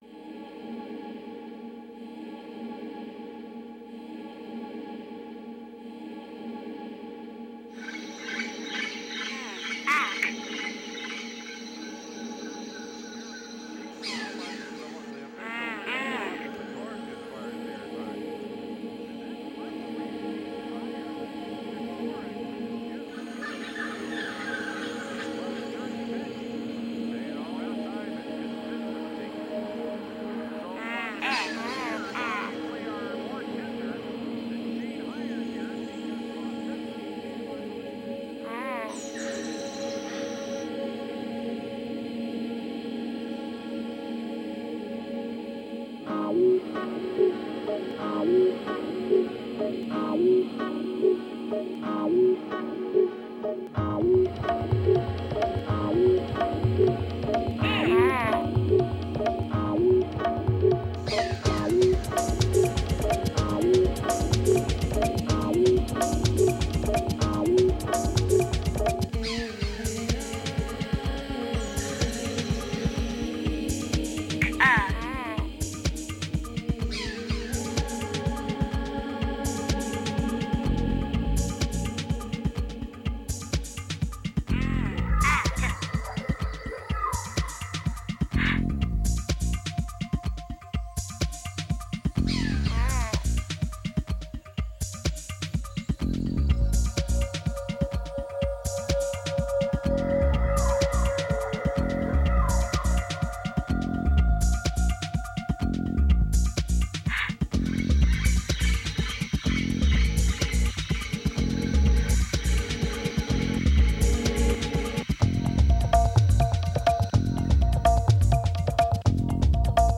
Genre: Dub, Downtempo, Tribal.